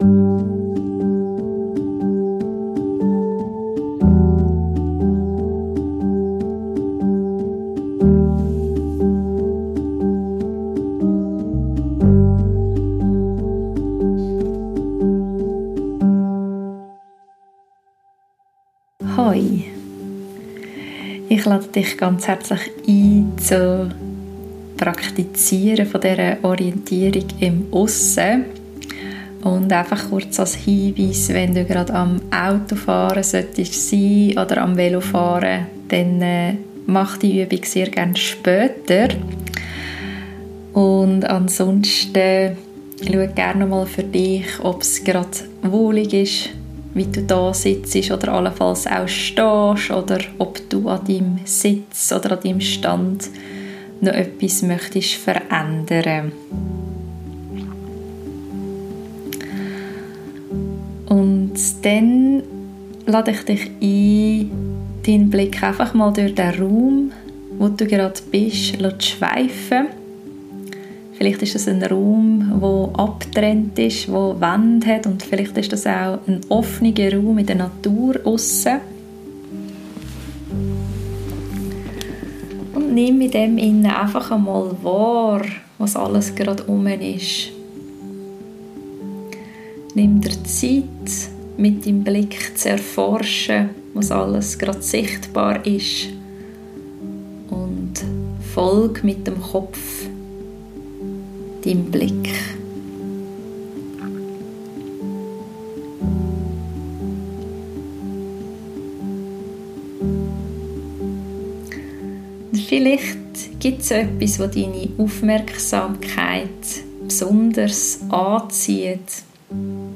Geführte Praxis zum Orientierung im aussen schaffen